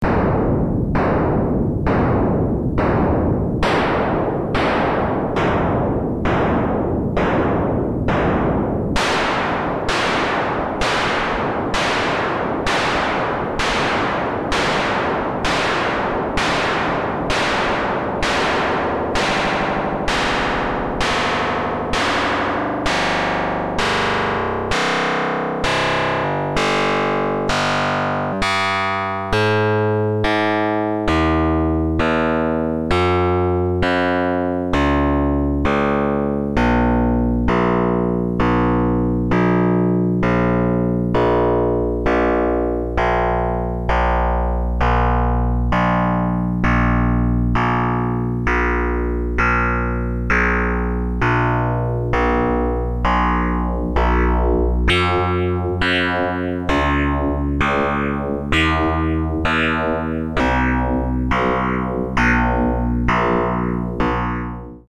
Here is an audio example, starting with noise played at a few different keys (speed) but from 18s up the lengh is gradually diminished up to the point you hear a tone After that at 30s the tone is played on a few keys. As a bonus, from 44s up a software BR filter is modulating the pattern.
here is a Noise changing into Pattern sound example
In the whole example, an LFO is stepping the pattern and also ramping the analog filter down at a 1Hz speed.